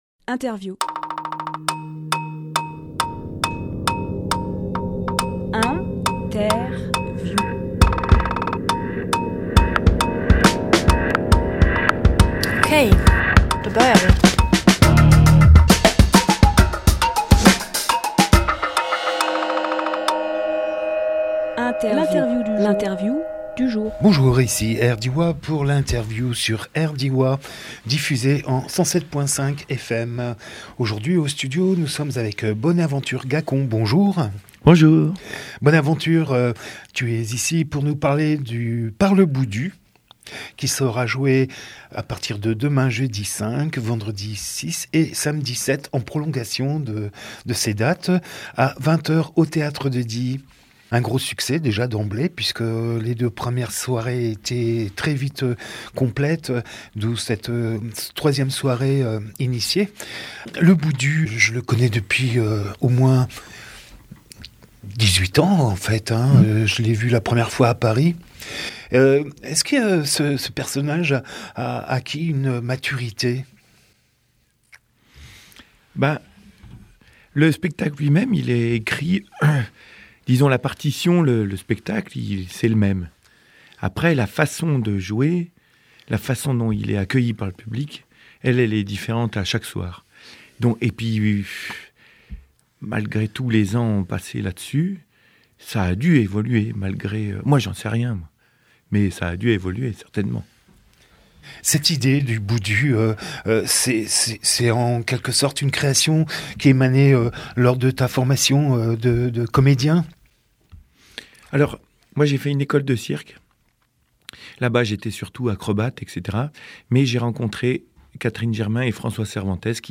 Emission - Interview Par le Boudu au Théâtre de Die Publié le 5 mars 2020 Partager sur…
04.03.20 Lieu : studio RDWA Durée